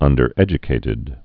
(ŭndər-ĕjə-kātĭd)